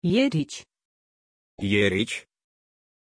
Pronuncia di Èric
pronunciation-èric-ru.mp3